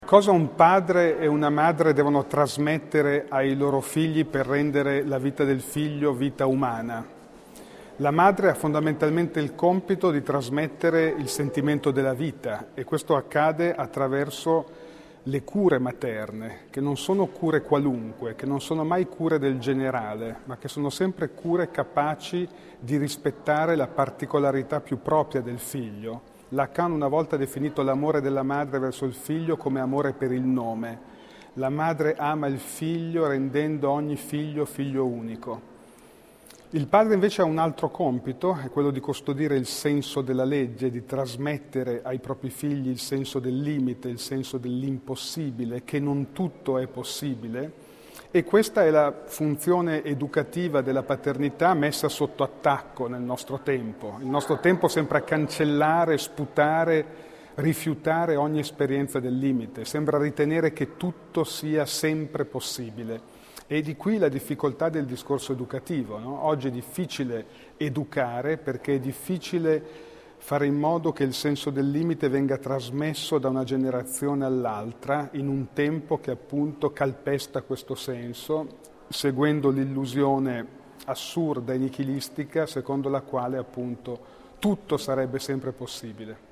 A parlarne oggi al Teatro Rosmini di Rovereto per Educa, il Festival dell’Educazione, è stato Massimo Recalcati , noto psicoanalista, docente e autore di numerosi saggi sul ruolo genitoriale, davanti a un pubblico numeroso e molto coinvolto di madri, padri, figlie e figli.